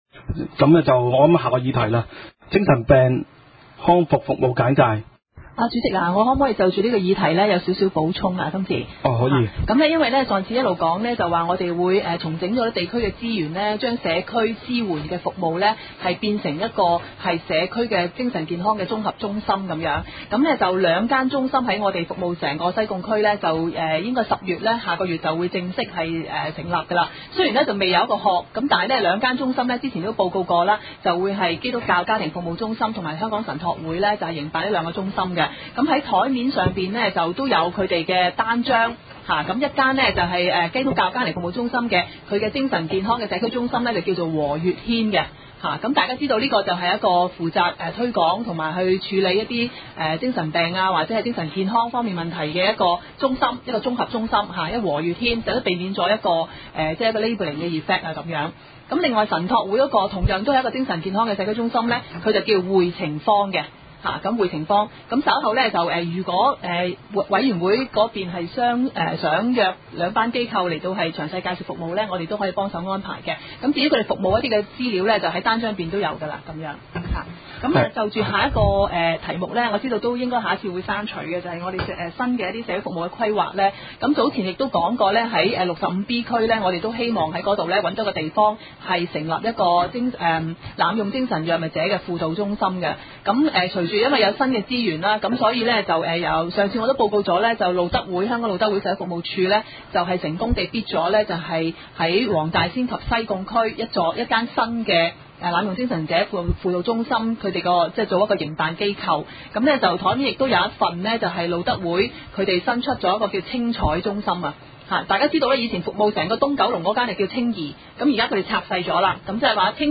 西貢區議會
地點：西貢區議會會議室